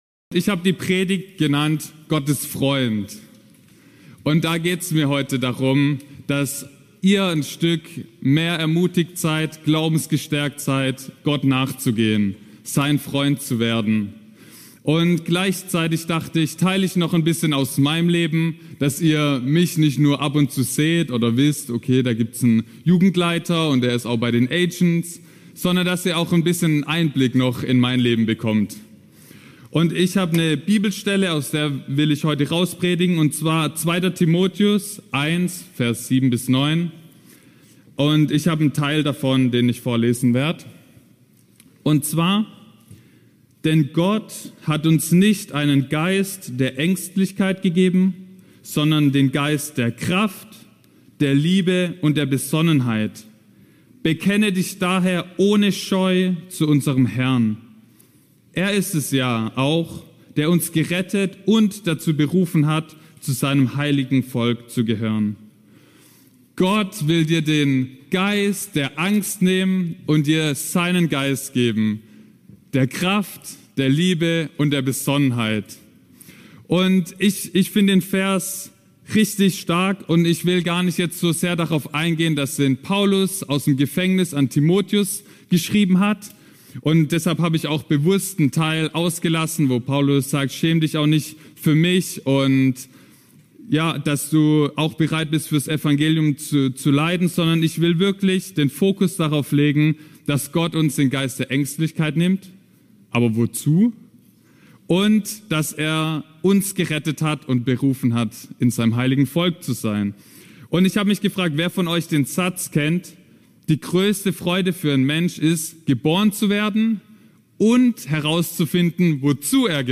Sonntagspredigt